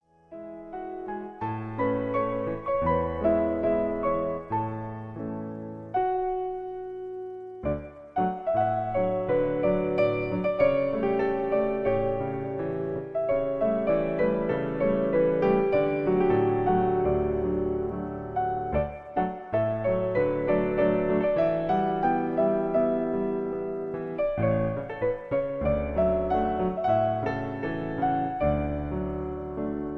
Reizendes Duett